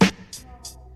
• Old School Bassy Hip-Hop Steel Snare Drum Sample G Key 138.wav
Royality free snare tuned to the G note. Loudest frequency: 1318Hz
old-school-bassy-hip-hop-steel-snare-drum-sample-g-key-138-TaP.wav